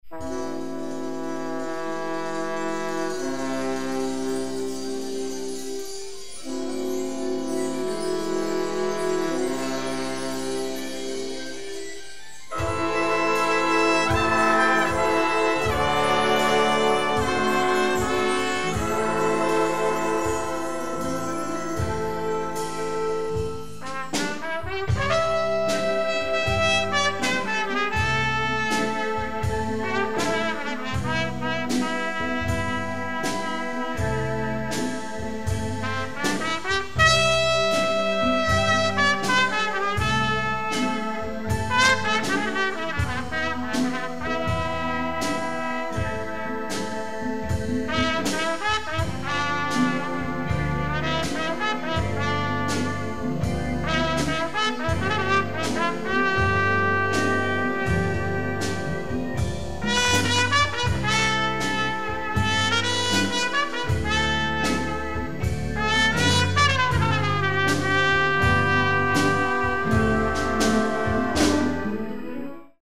Konzert 2006 -Download-Bereich
-------Das Orchester-------